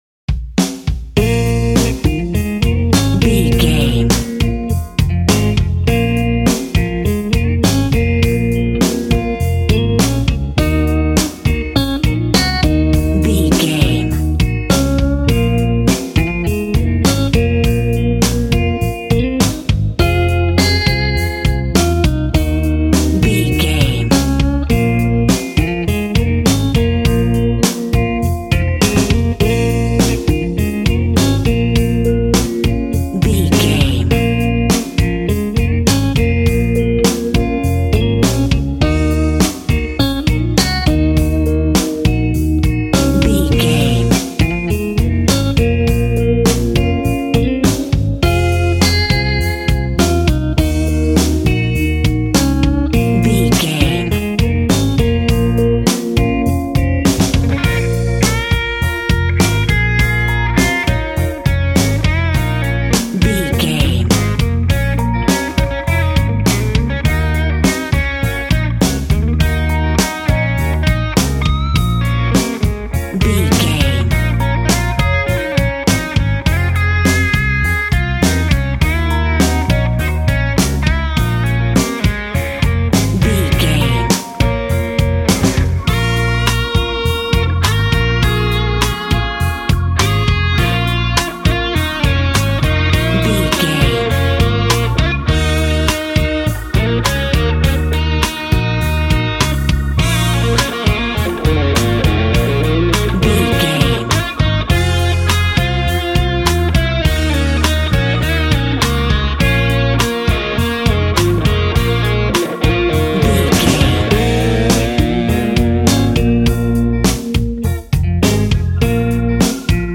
Aeolian/Minor
B♭
sad
mournful
bass guitar
electric guitar
electric organ
drums